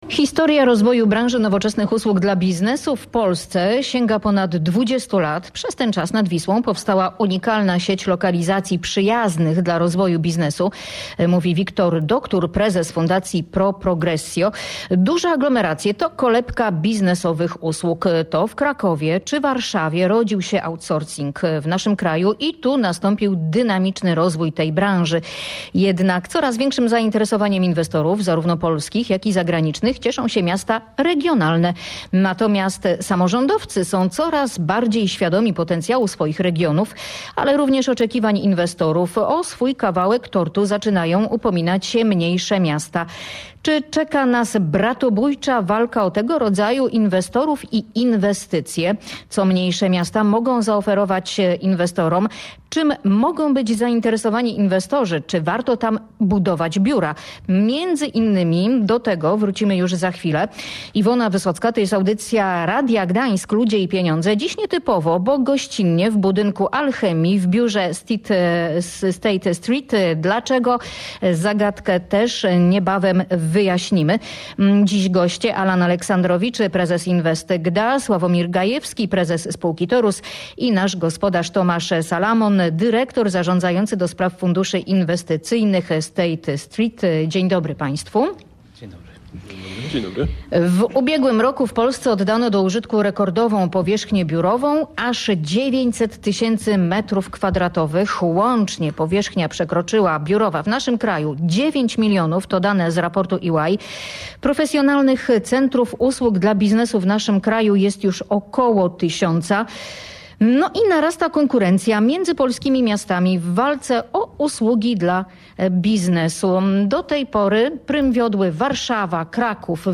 Sprawę komentowali eksperci w audycji Ludzie i Pieniądze.